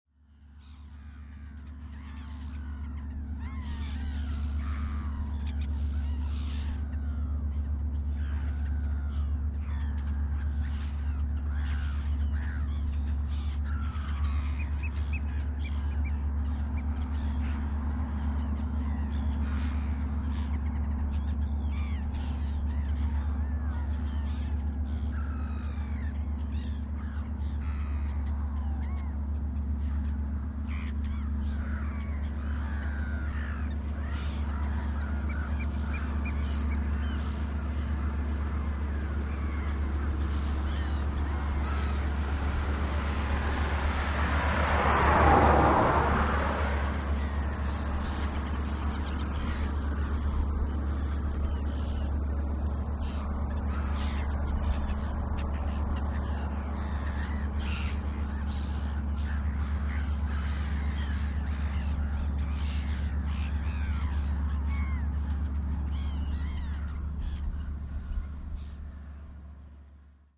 Port seaside night birds noisy seagulls ambience